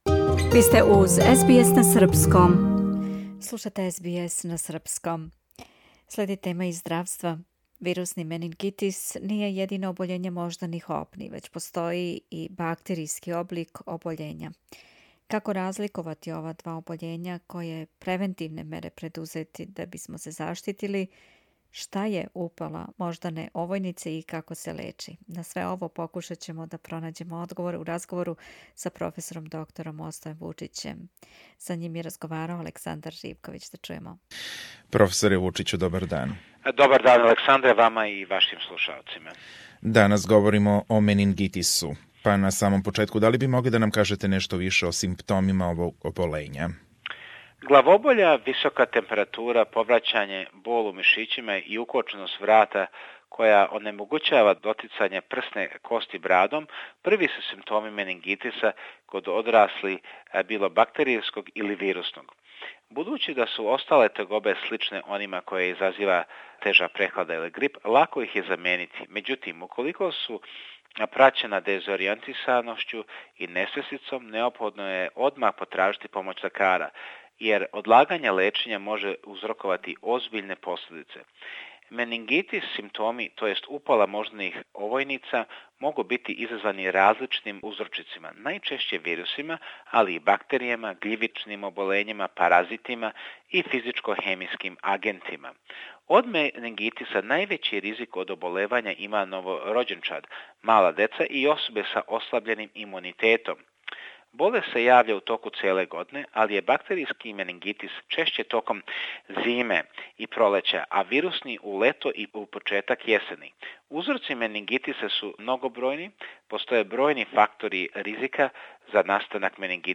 На све ово покушаћемо да пронађемо одговоре у разговору